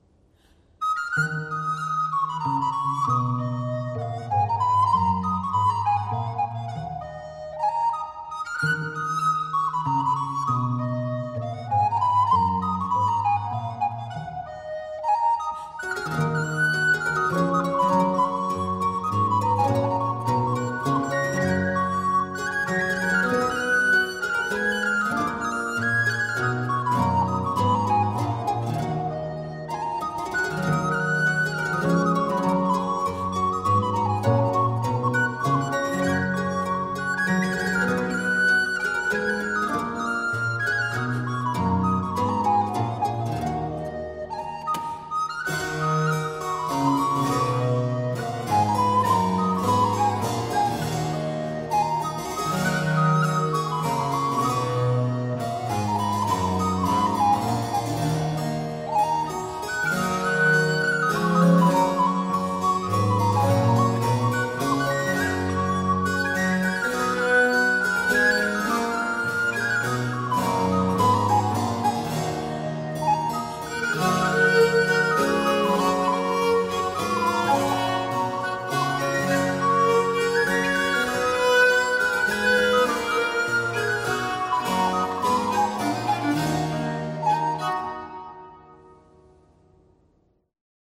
The Flemish ensemble Musica Gloria will arrive to Prague with an invitation to the most distinguished society of the Kingdom of England of the second half of the 17th and early 18th centuries.
viola da gamba
theorbo, Baroque guitar